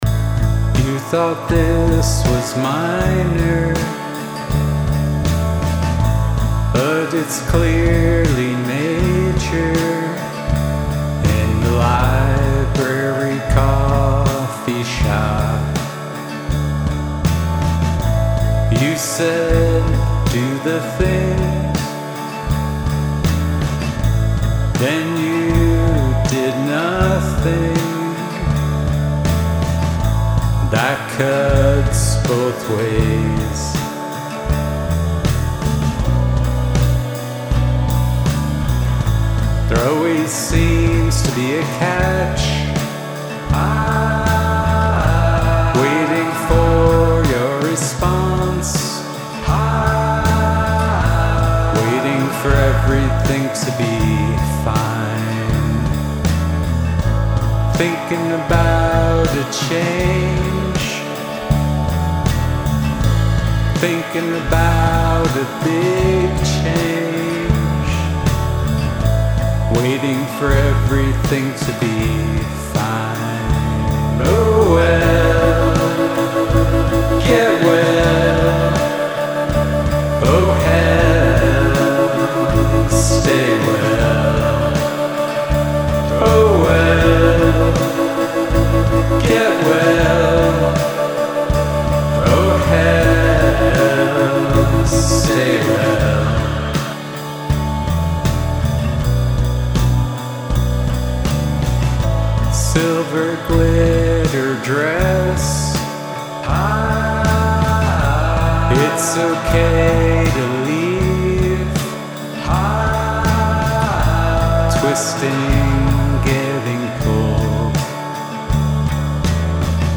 Yes, that's way better, the snare is back to normal tonally, and centered in the mix. I think there is a little less mud then previous mixes, but that wasn't what was bothering me about the mix initially, it was more the left right balance.
I did wind up lowering the snare just 1db because it was bugging me. Lol I also turned up the right organ a hair just using headphones to balance it with the acoustic.